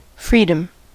Ääntäminen
US : IPA : [ˈfriː.dəm]